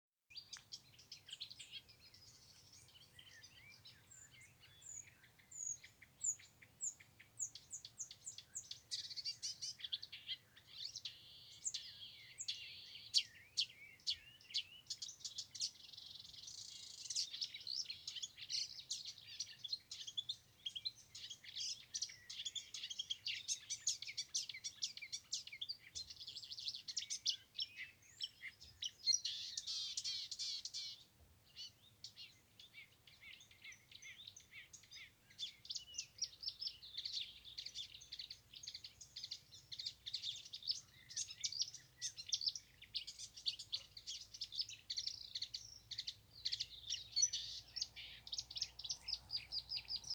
Marsh Warbler, Acrocephalus palustris
Administratīvā teritorijaLudzas novads
StatusSinging male in breeding season